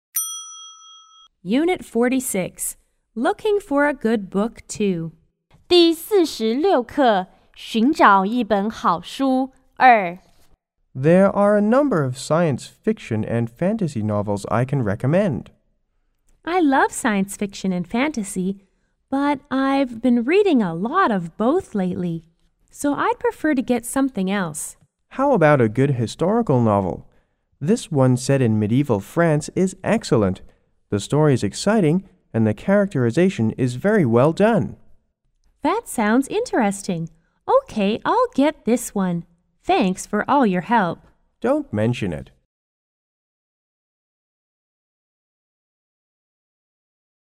S= Salesperson C= Customer